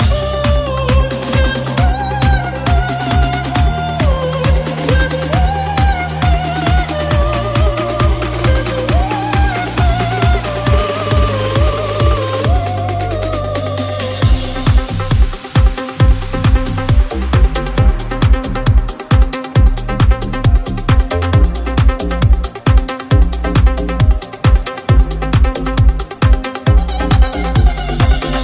Genere: dance,disco,pop,rock,ballad,techno,folk,etnic